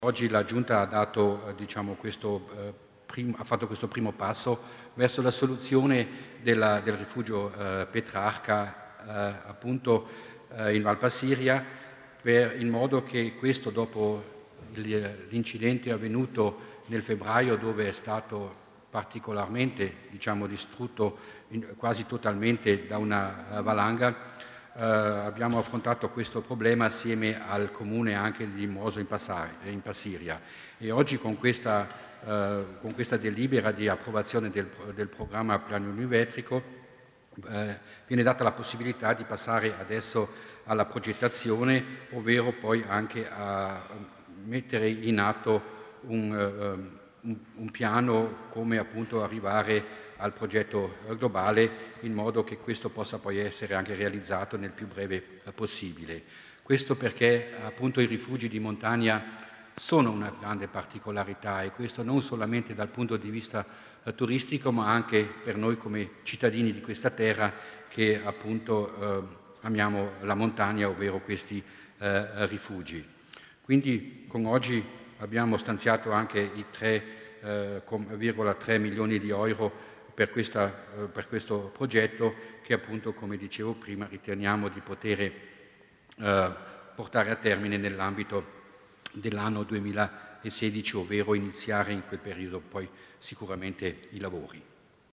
L'Assessore Mussner illustra il progetto per il rifugio Petrarca